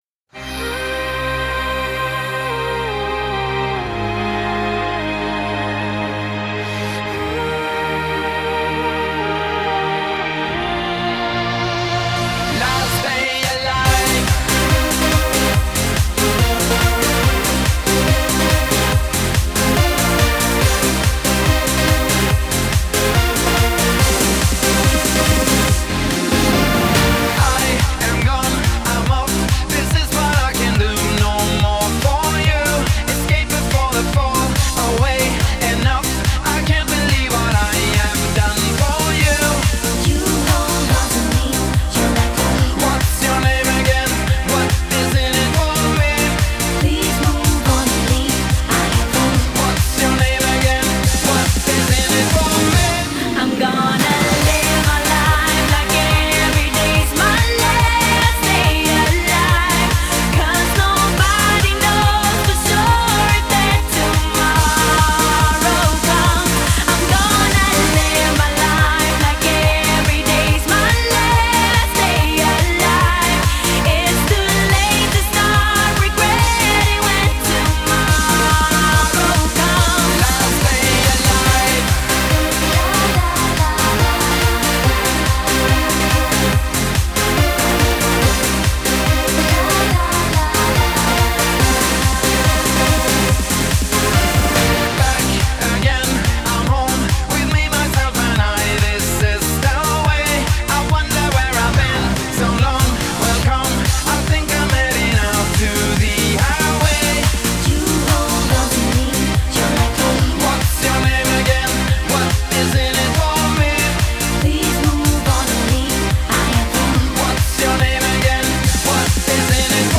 Жанр: Eurodance, Pop